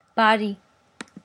The voiced bilabial plosive or stop is a type of consonantal sound used in many spoken languages.
Armenian Eastern[2] բարի/bari
[bɑˈɾi] 'kind'